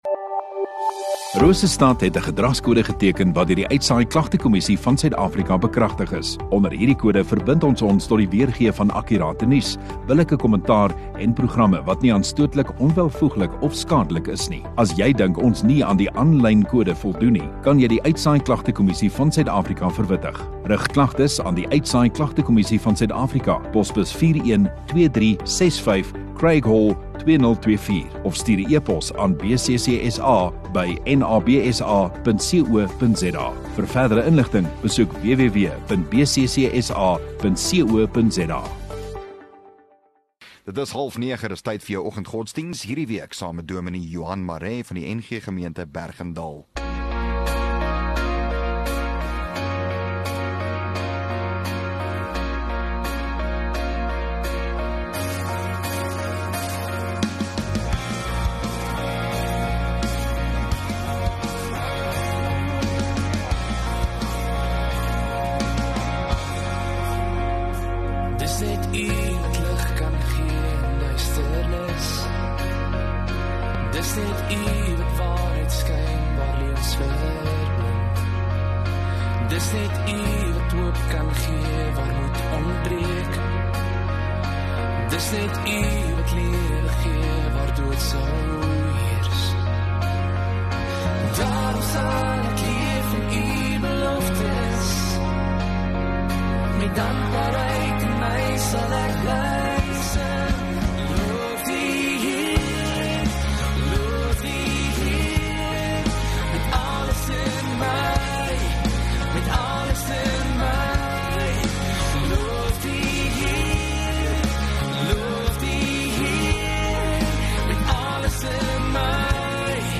2 Dec Dinsdag Oggenddiens